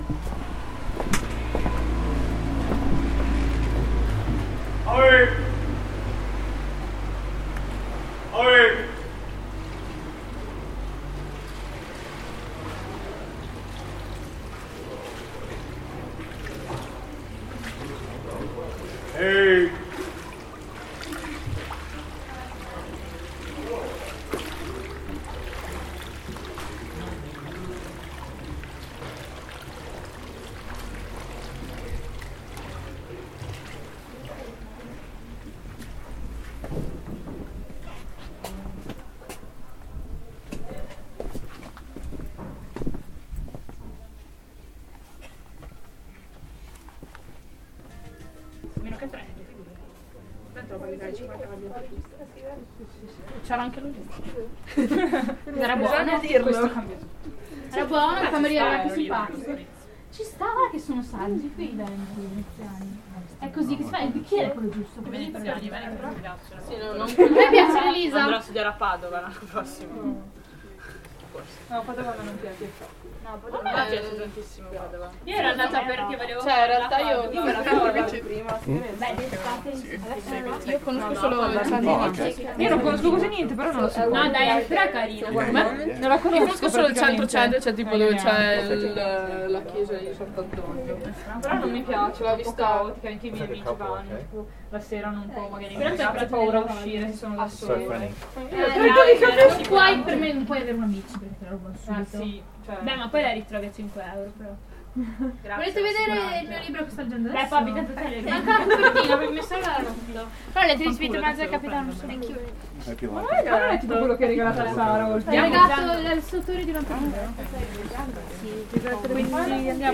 Acqua Alta bookshop, Venice